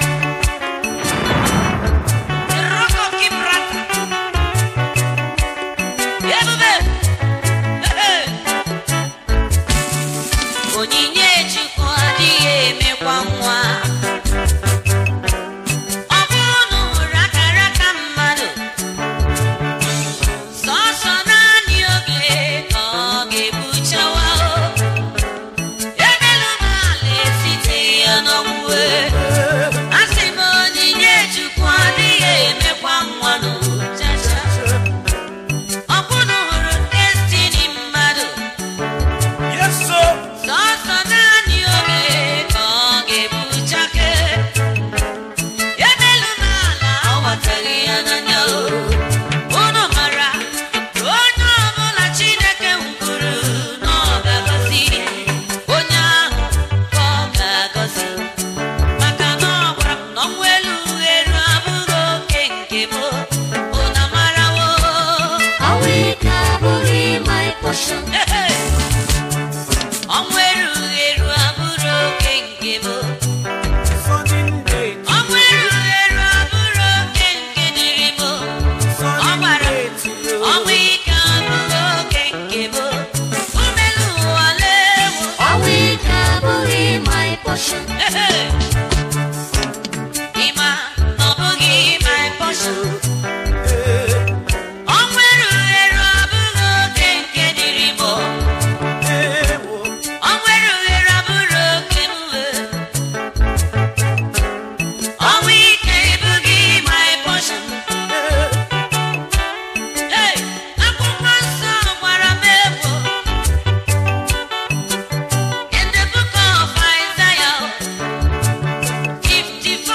Igbo Gospel
worship single